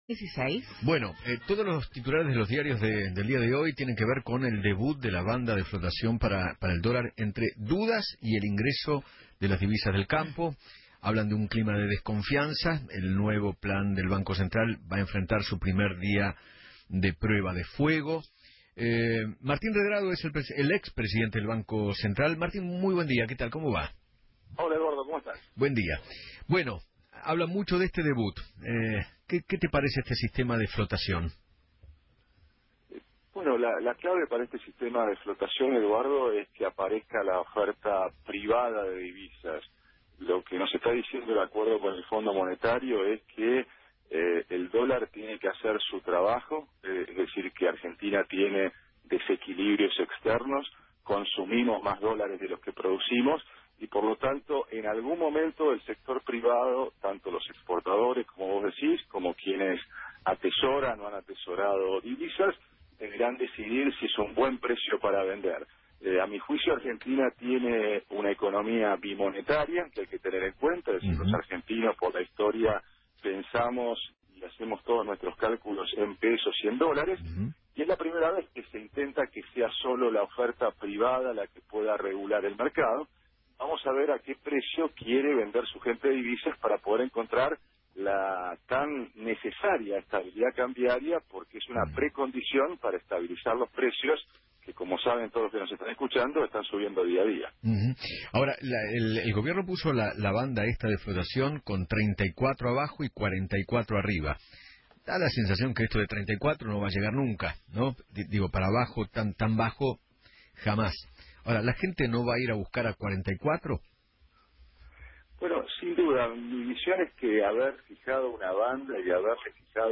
Martín Redrado, ex Presidente del Banco Central, habló en Feinmann 910 y aseguró que “la clave para el sistema de flotación es que aparezca la oferta privada de divisas” y que “el acuerdo con el Fondo nos dice que el dólar debe hacer su trabajo, que Argentina tiene desequilibrios externos, consumimos más dolares de lo que producimos. Ahora el sector privado deberá decidir si es un buen precio para vender.”